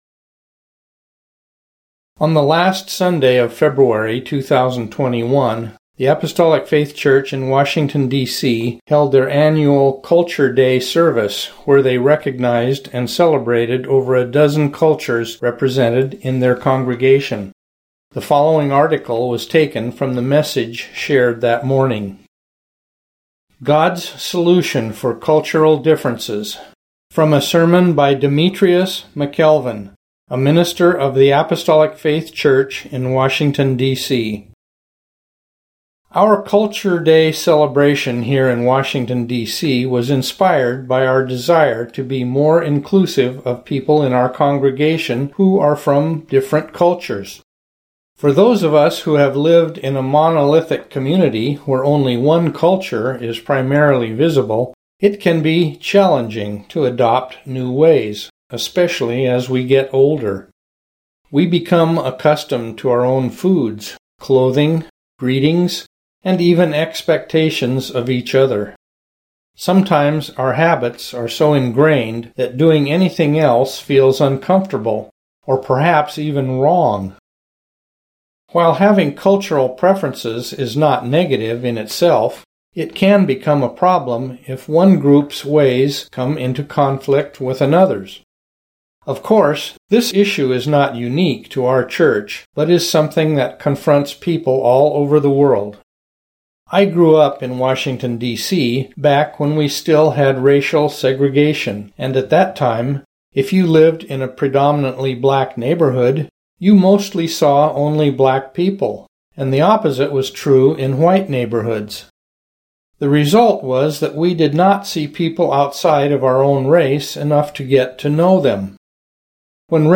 On the last Sunday of February 2021, the Apostolic Faith Church in Washington D.C. held their annual Culture Day service where they recognized and celebrated over a dozen cultures represented in their congregation. The following article was taken from the message shared that morning.